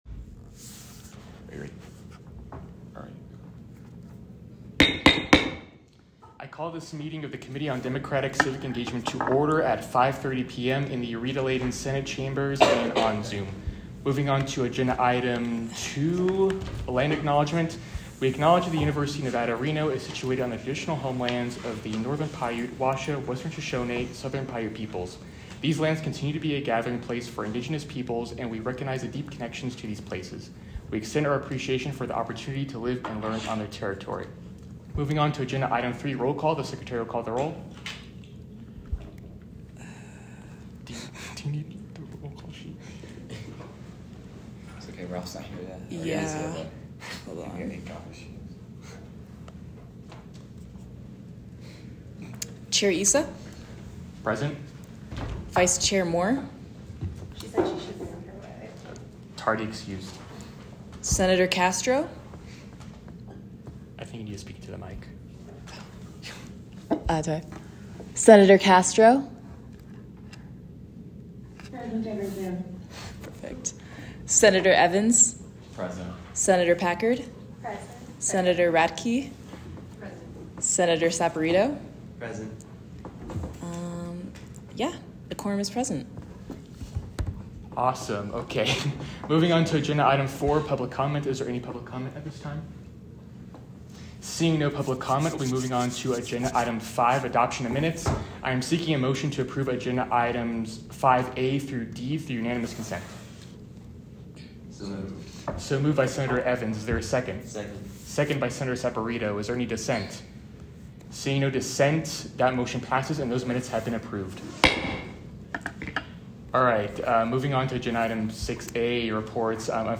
Location : Rita Laden Senate Chambers
Audio Minutes